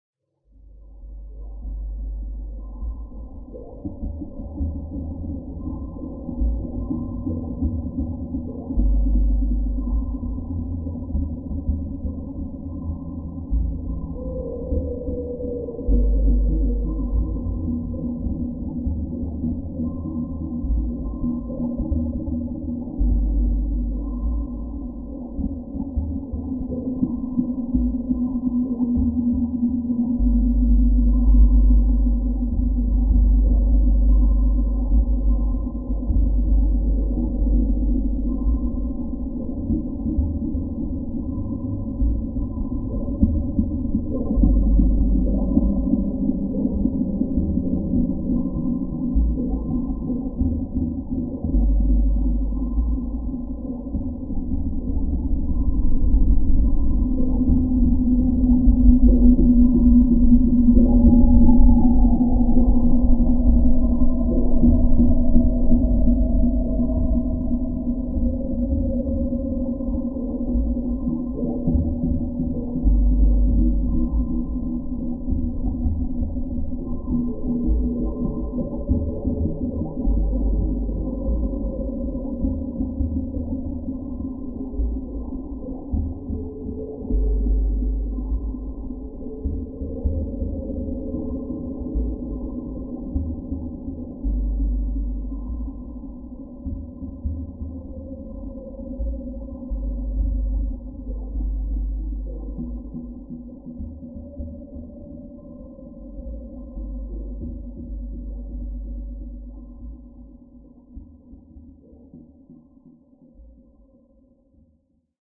underwater_ambient.ogg